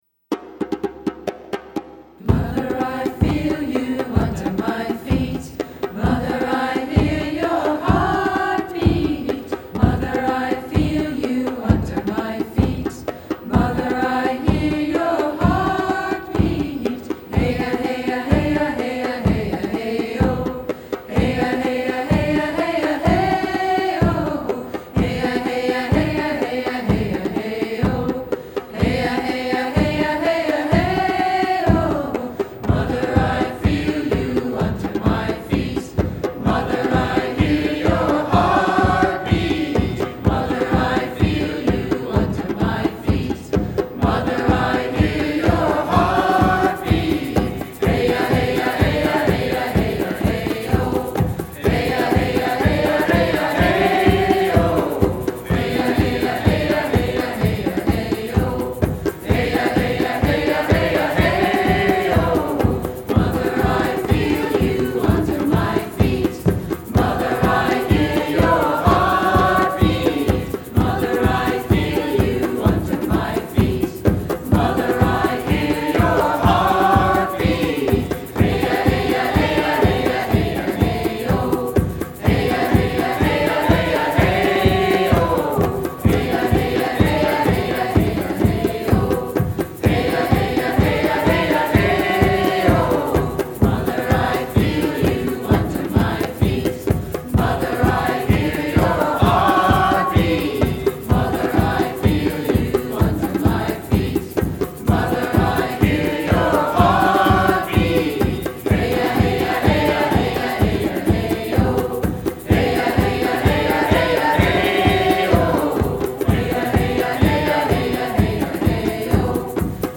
(Native American)